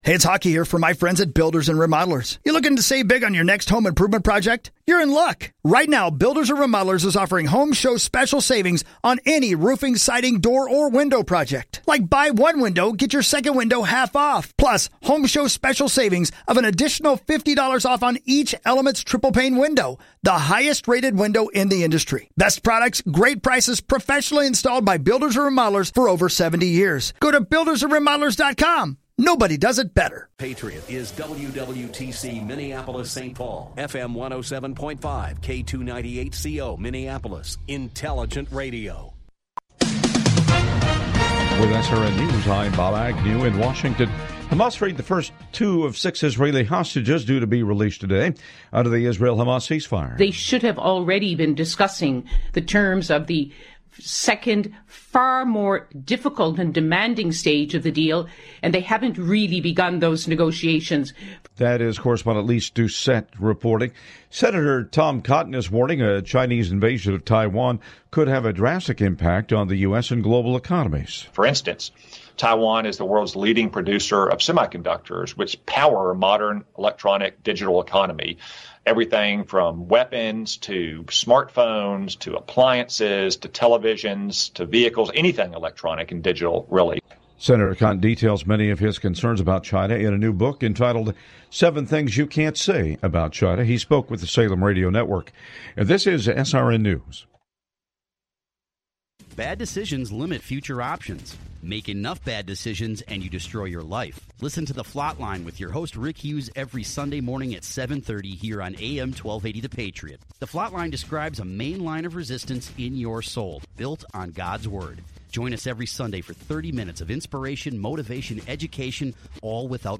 Don’t miss this empowering conversation!